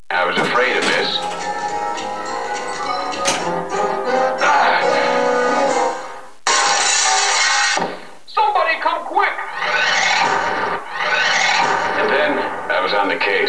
At the beginning you will hear "I was afraid of this".
GAME START: